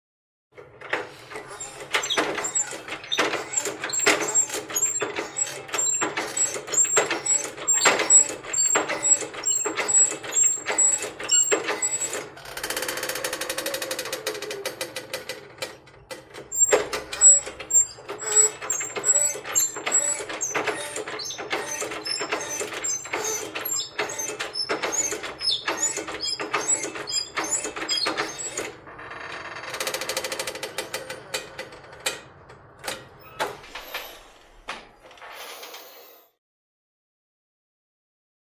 Hospital Bed; Movement; Hospital Room Bed; Hydraulic Pump Up / Release Down / Squeak, Close Perspective.